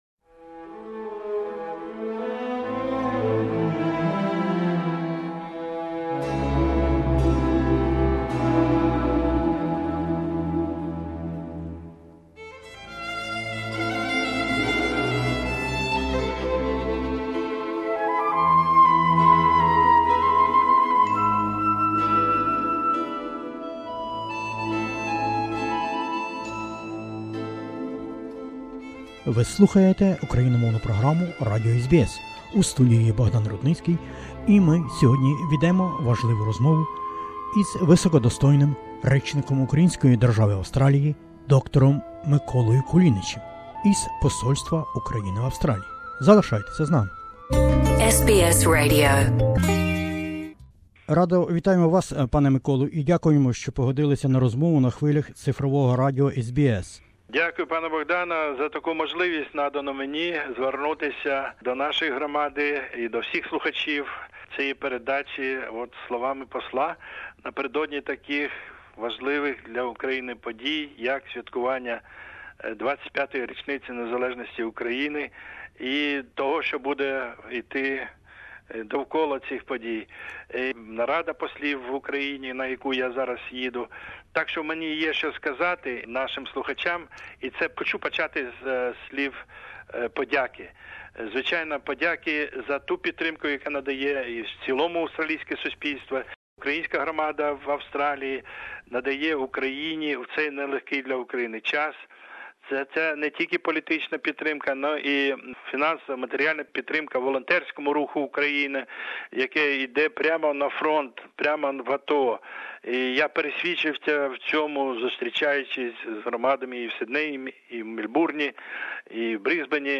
The Honourable Dr Mykola Kulinich, the Ambassador of Ukraine in Australia Source: SBS Ukrainian